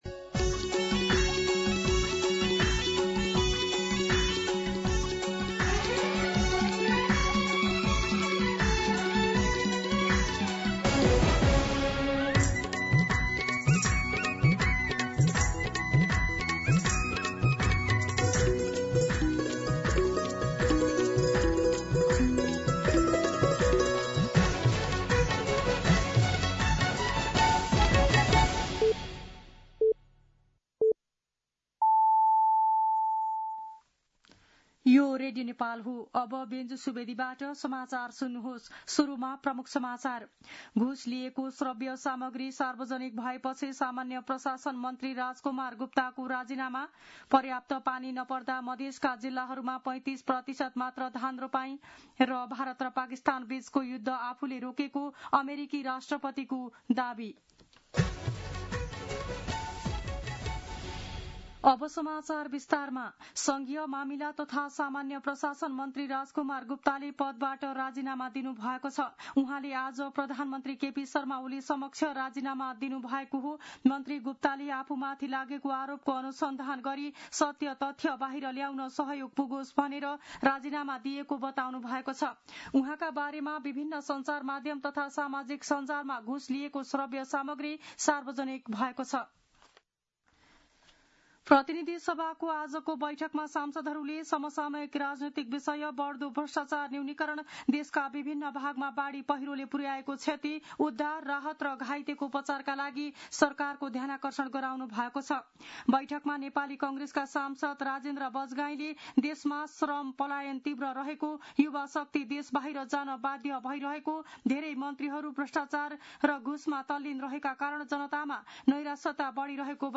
दिउँसो ३ बजेको नेपाली समाचार : ३१ असार , २०८२